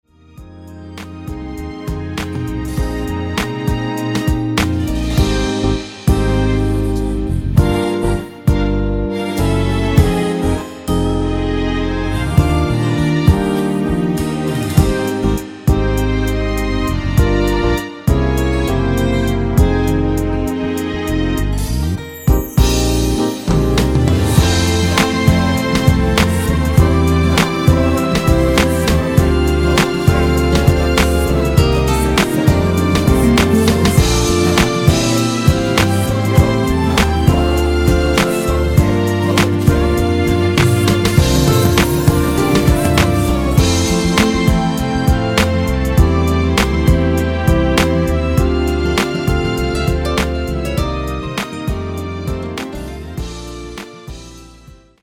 Eb
앞부분30초, 뒷부분30초씩 편집해서 올려 드리고 있습니다.